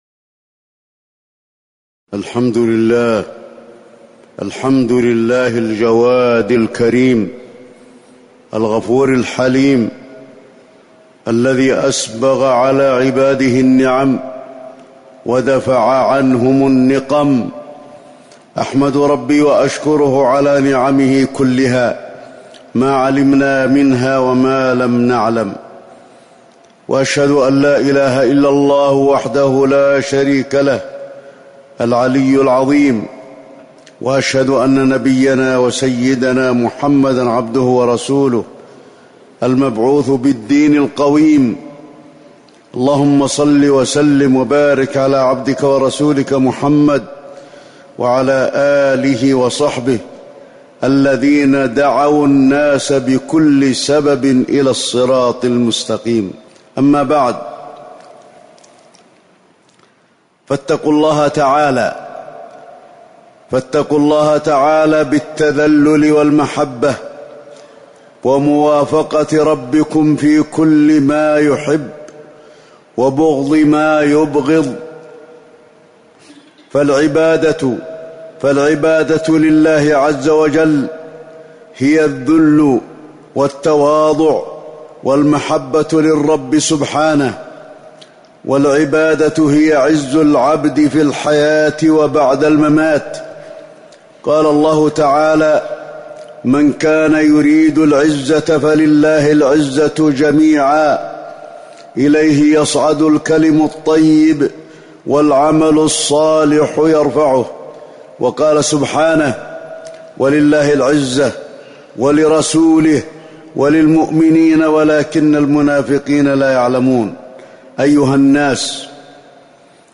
تاريخ النشر ٨ ذو الحجة ١٤٤٠ هـ المكان: المسجد النبوي الشيخ: فضيلة الشيخ د. علي بن عبدالرحمن الحذيفي فضيلة الشيخ د. علي بن عبدالرحمن الحذيفي اسم الله الشكور